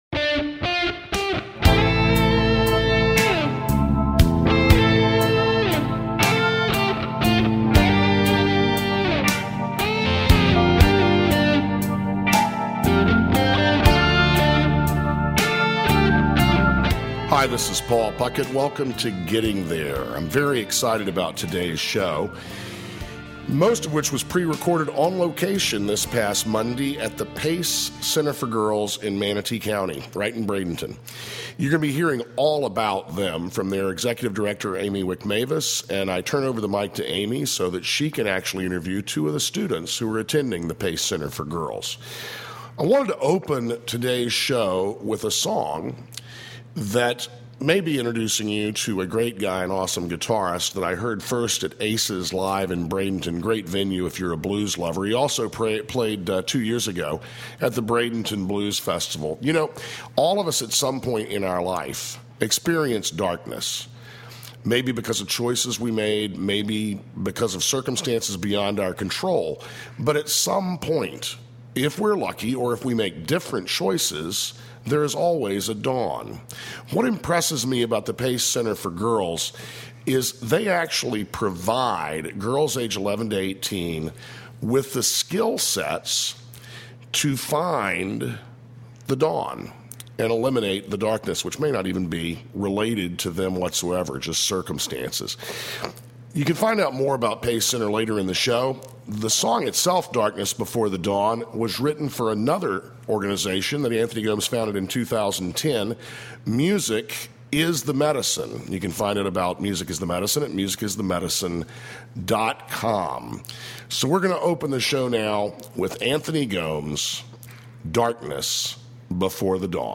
Talk Show Episode
In addition to money issues, the conversation will include gardening, cooking, books, travel, and interviews with guests from the financial field as well as chefs, gardeners, authors, and spiritual leaders.